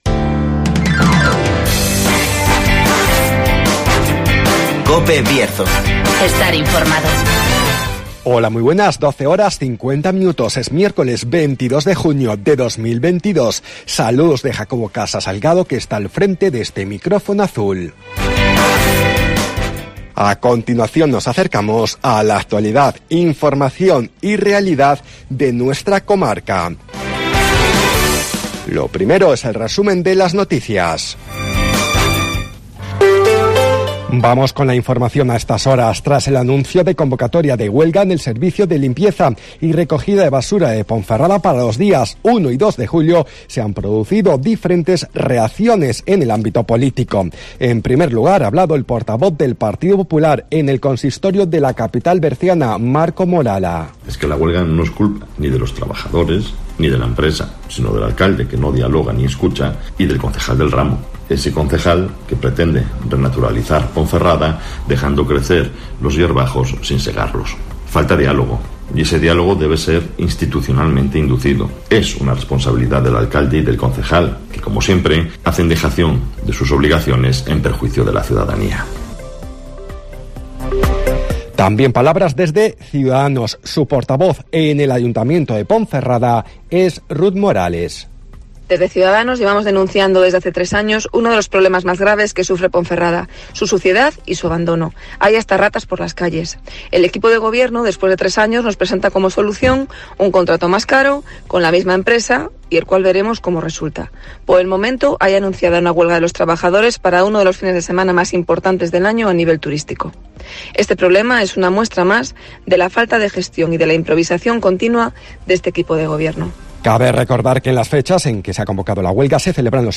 AUDIO: Resumen de las noticias, El Tiempo y Agenda